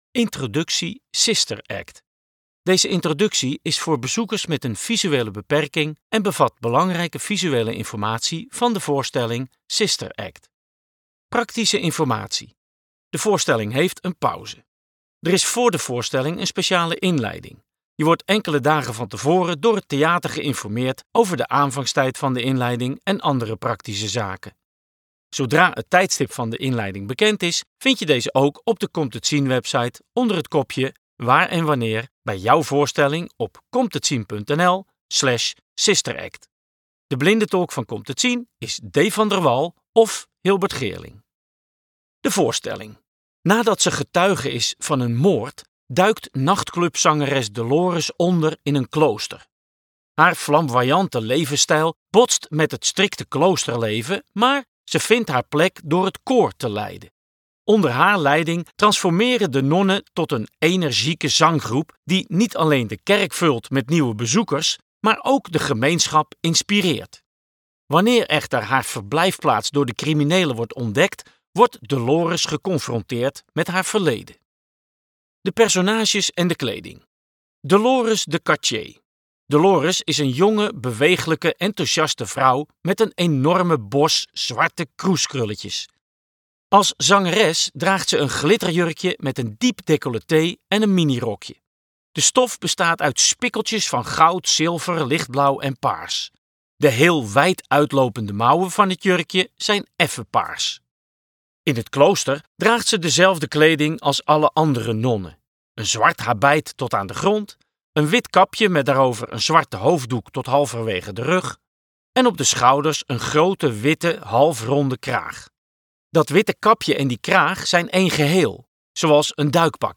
musicalicoon audiodescriptie